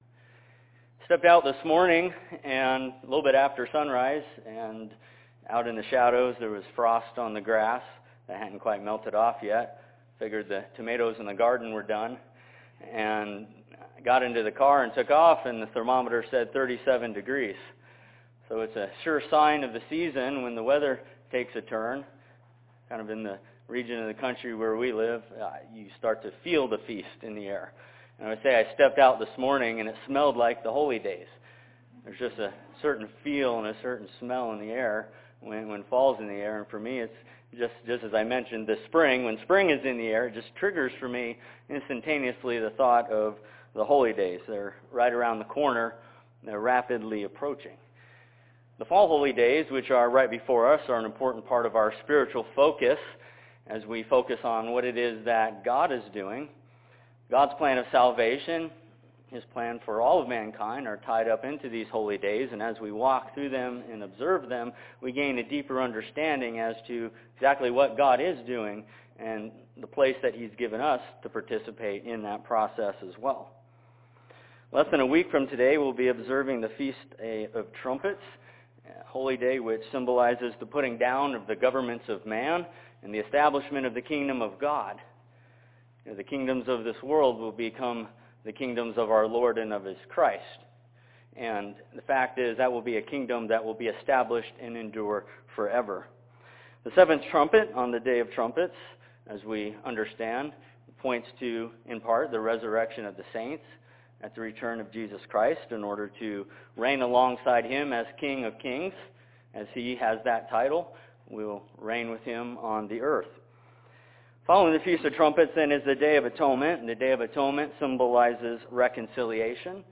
Sermon in Spokane, Washington.